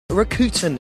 They mainly stress it on the middle vowel, rə-KU-tən, so that it rhymes with gluten, Newton and Luton. Here’s the interviewer: